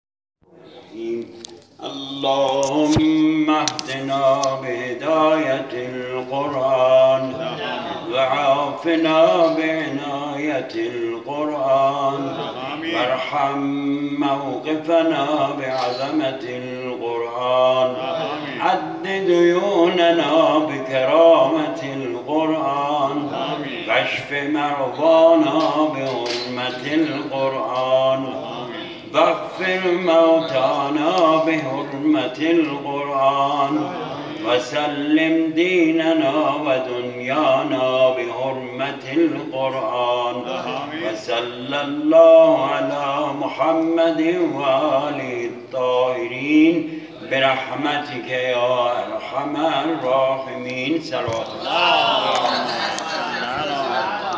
به محض ورود به حسینیه، اولین چیزی که جلب توجه می‌کند، تلاوت قرآن کریم با لهجه شیرین آذری است و در آنجا دیگر از عناوین بین‌المللی و دنیایی و سیستم صوت آن‌چنانی و به کار بردن صوت و لحن خاص خبری نیست و قرآن را به روش سنتی و لذت‌بخش تلاوت می‌کنند.